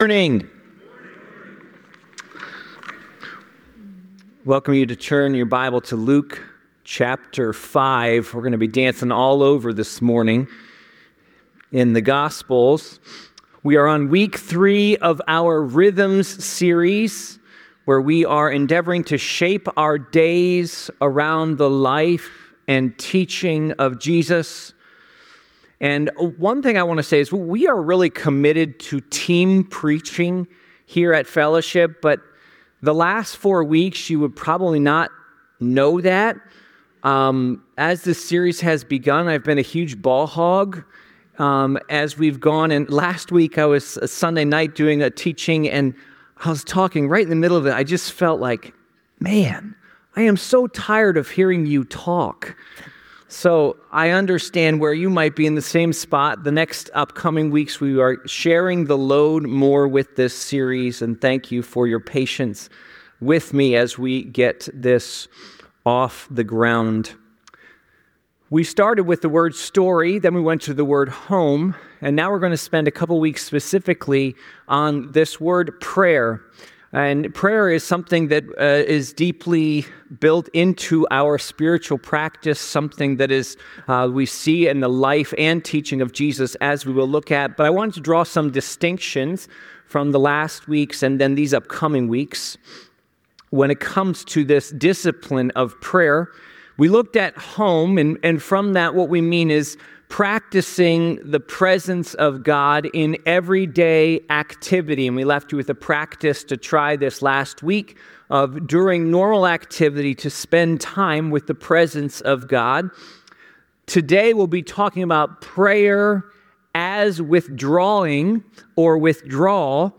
The sermon highlights Jesus's frequent practice of withdrawing to solitary places for prayer, which was a prioritized spiritual discipline in his life.